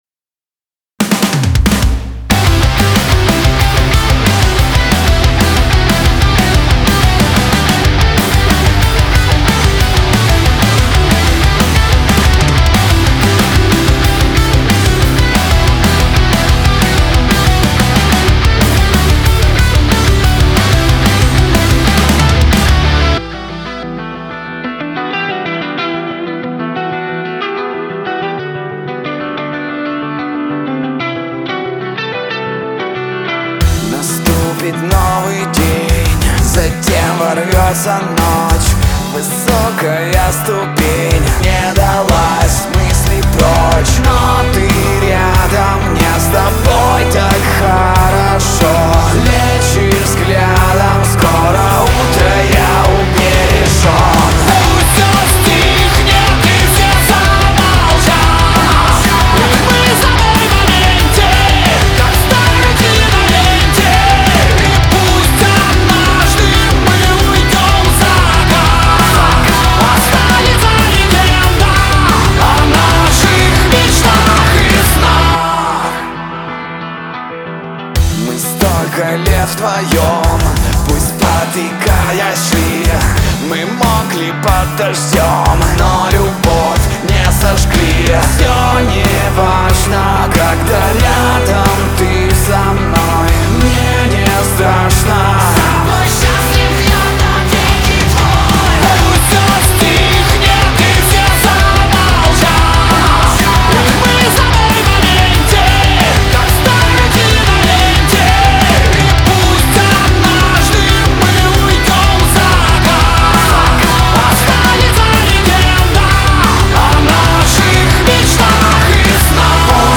Жанр: Metal